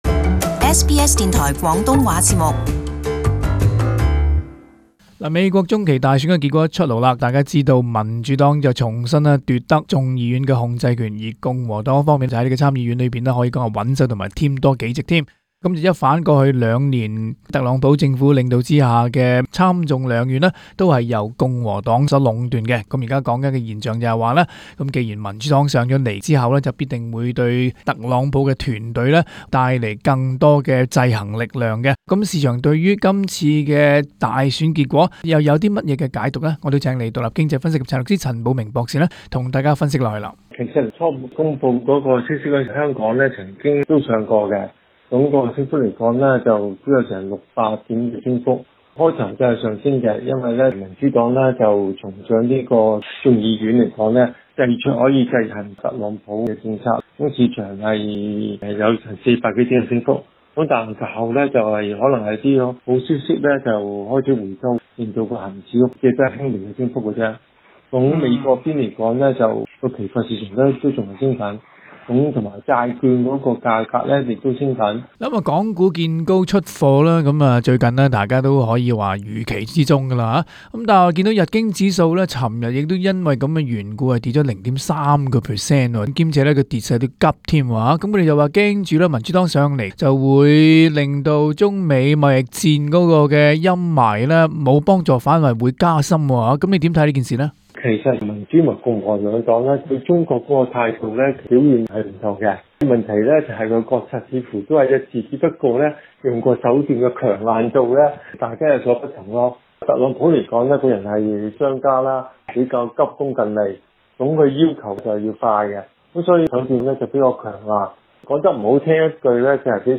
詳細情況請收聽以下的訪問。